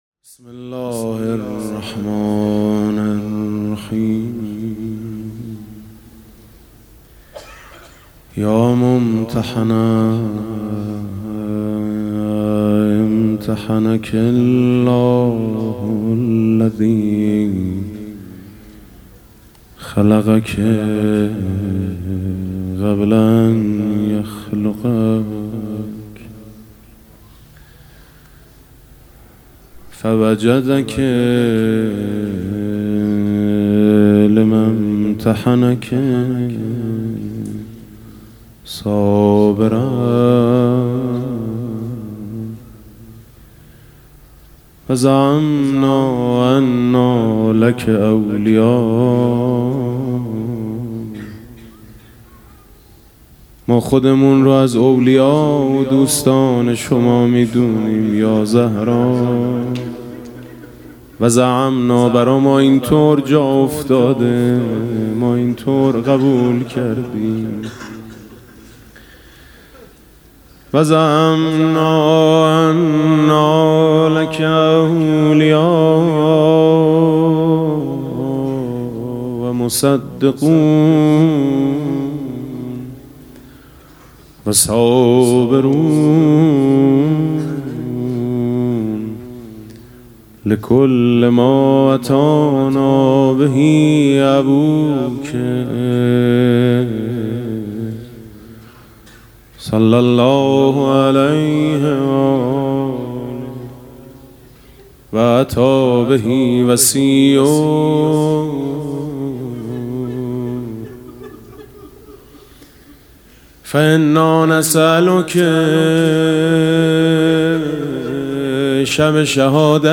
[آستان مقدس امامزاده قاضي الصابر (ع)]
عنوان: شب شهادت حضرت زهرا (س)